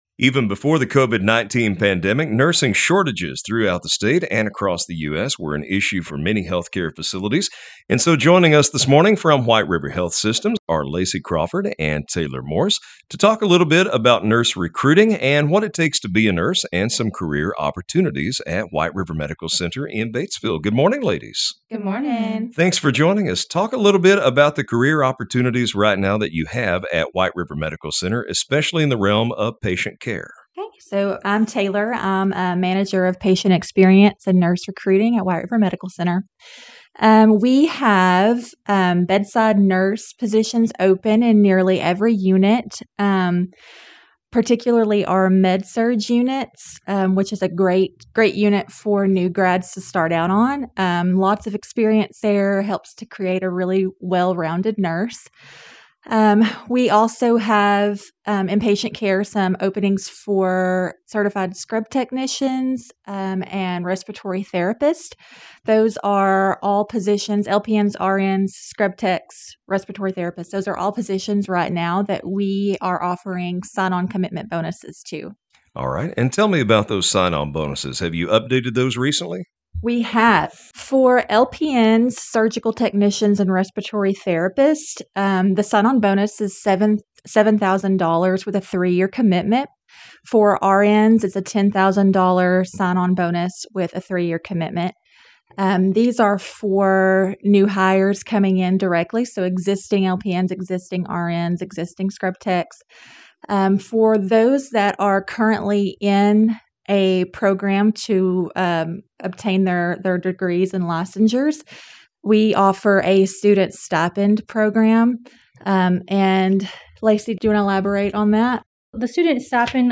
WRMC-Nursing-Interview-White-River-Now.mp3